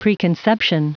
Prononciation du mot preconception en anglais (fichier audio)
Prononciation du mot : preconception